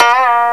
KOTOBEND.WAV